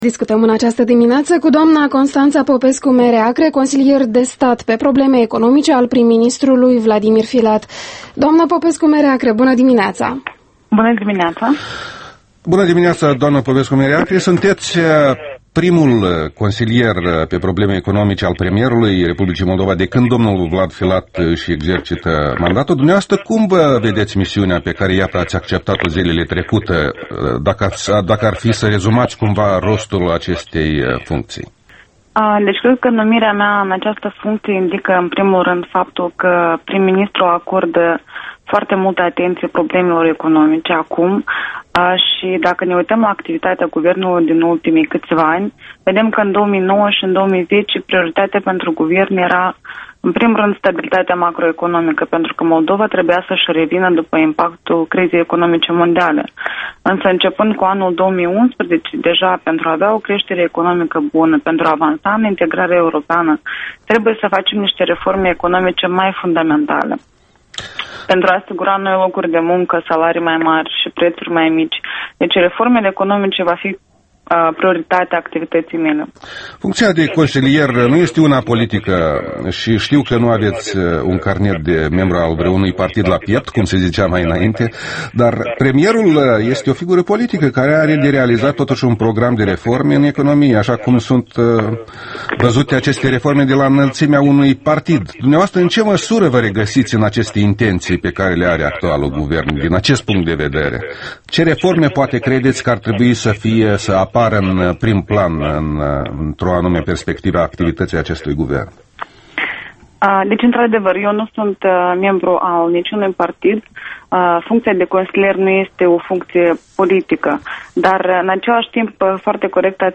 Interviul matinal El